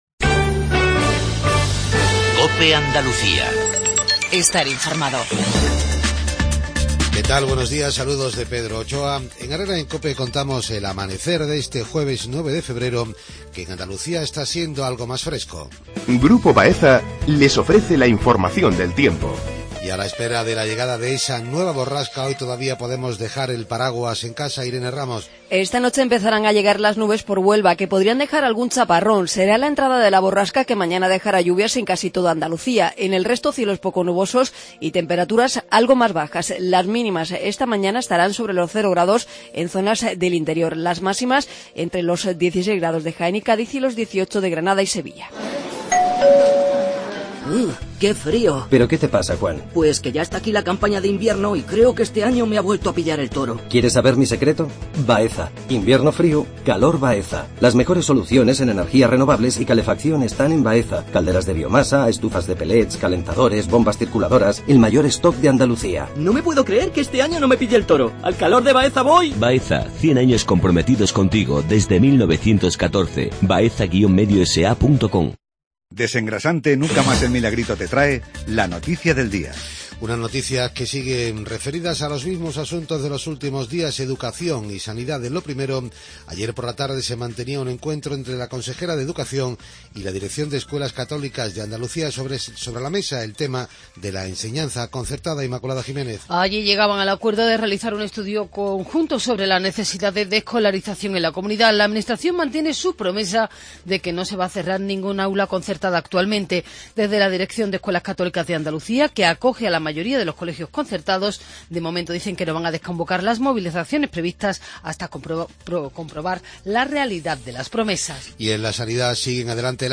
INFORMATIVO REGIONAL/LOCAL MATINAL 7:50